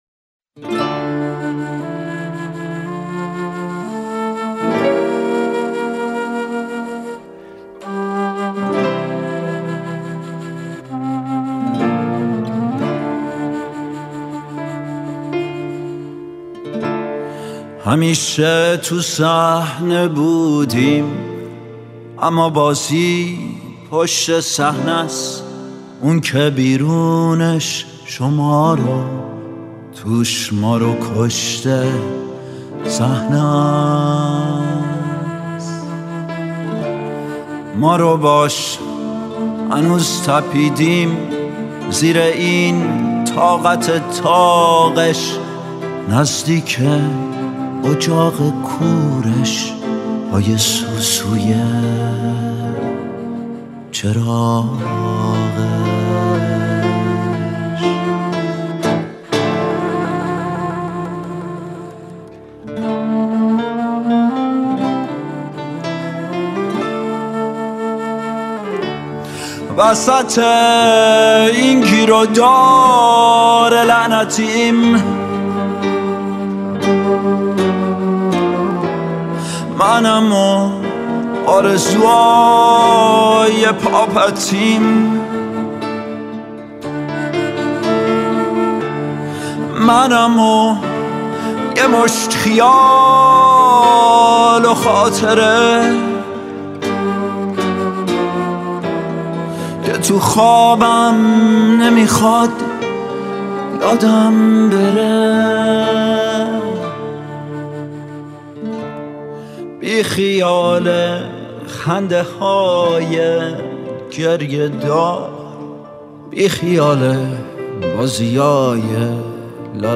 ترانه